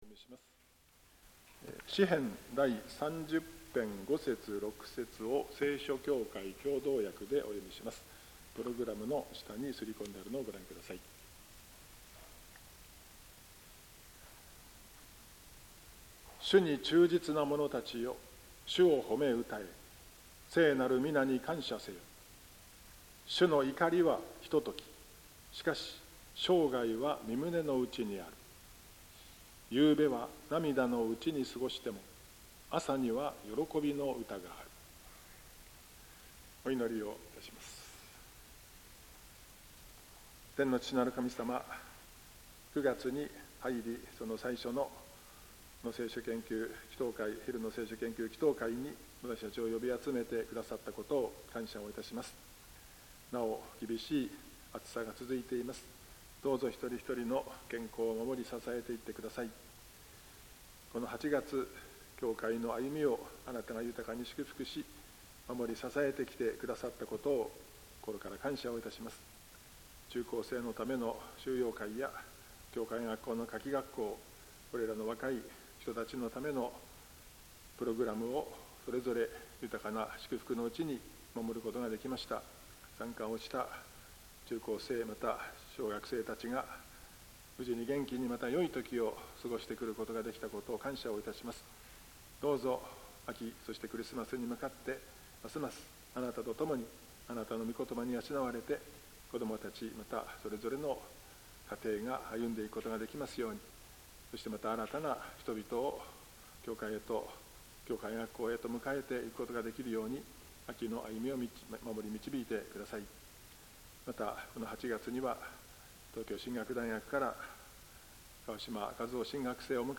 ２０２５年９月の聖句についての奨励（９月３日 昼の聖書研究祈祷会）